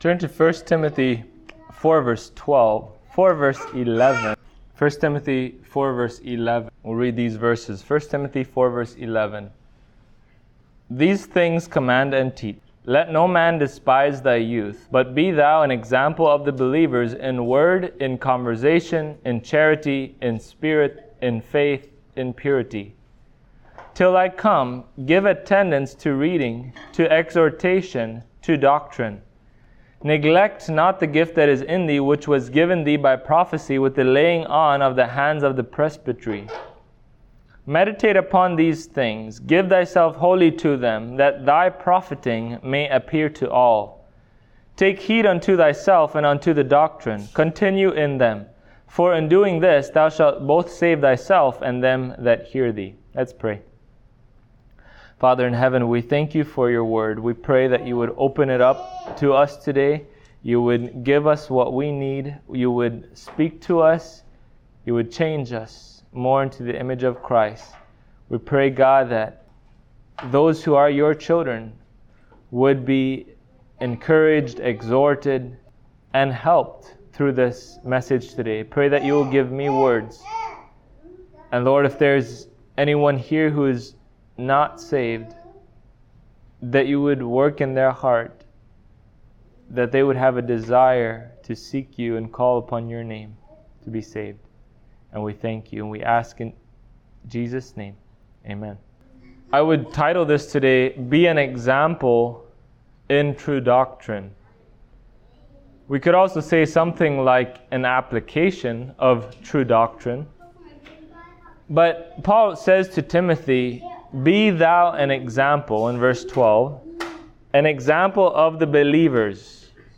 1Tim 4:12-16 Service Type: Sunday Morning God has called Believers to be an example in faith in conduct and in purity.